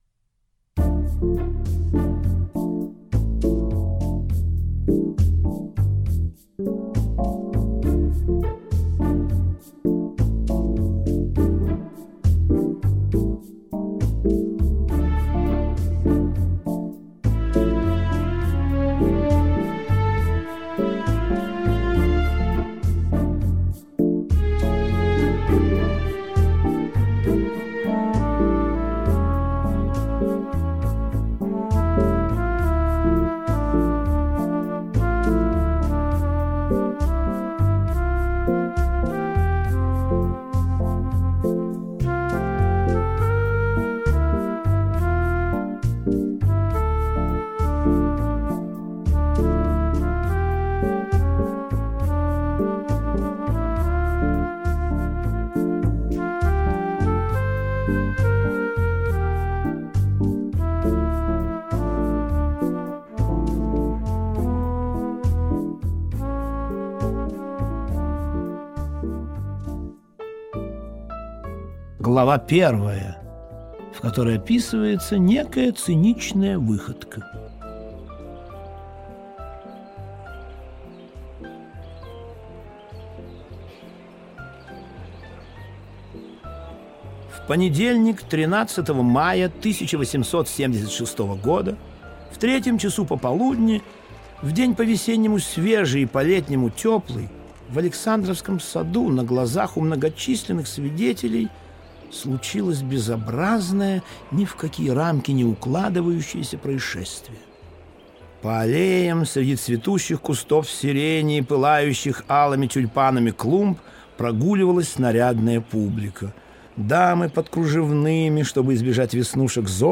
Аудиокнига Азазель - купить, скачать и слушать онлайн | КнигоПоиск